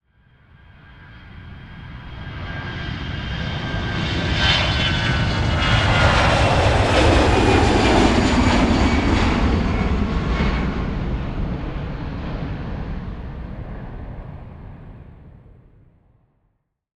دانلود آهنگ سوت هواپیما 8 از افکت صوتی حمل و نقل
دانلود صدای سوت هواپیما 8 از ساعد نیوز با لینک مستقیم و کیفیت بالا
جلوه های صوتی